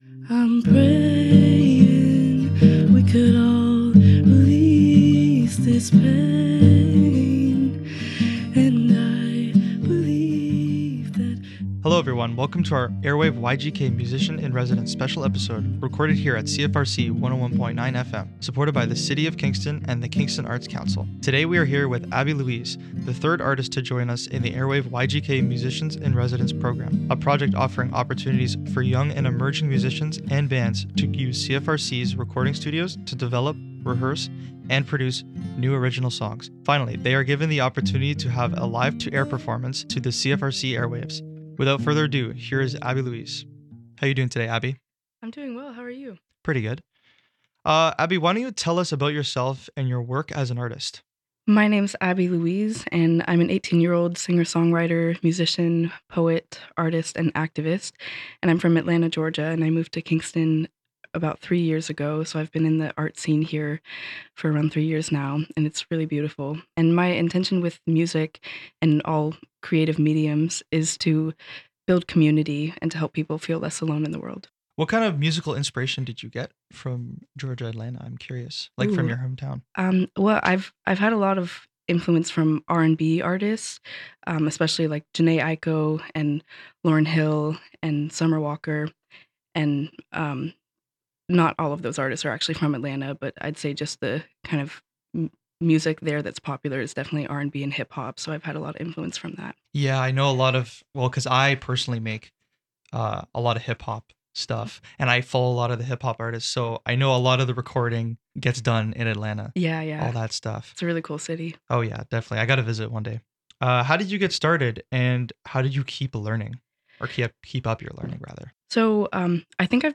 Interview and Performance at CFRC Studios - CFRC Podcast Network